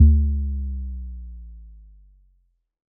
Bass Power Off 8.wav